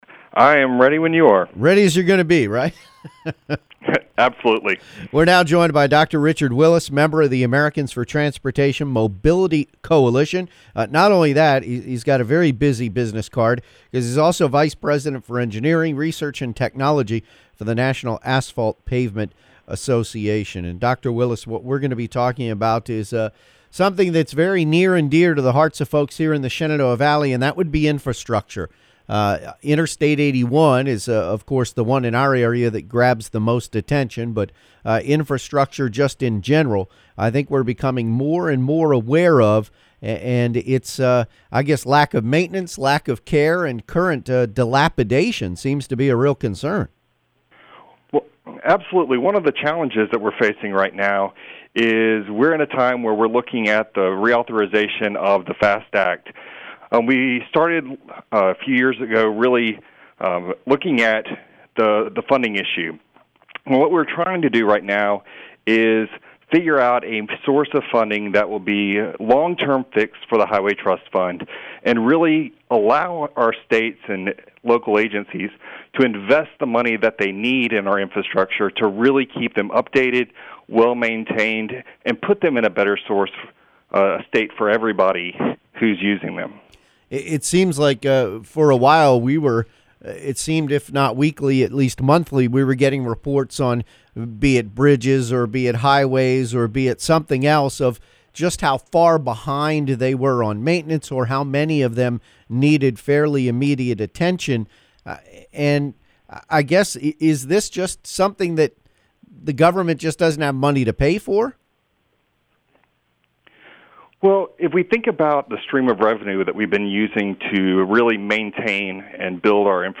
Radio interviews: Tuesday